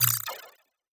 Hi Tech Alert 4.wav